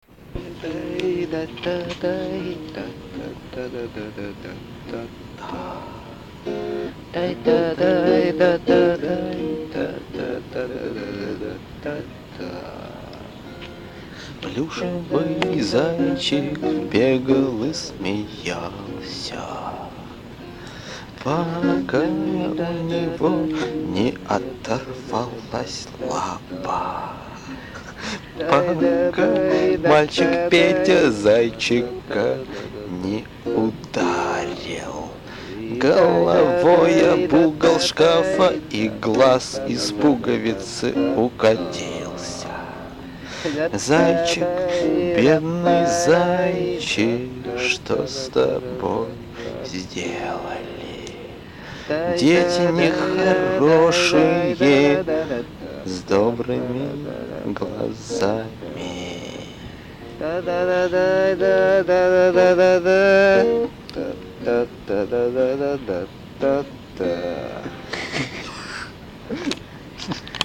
вокал
бэк-вокал
гитара     Обложка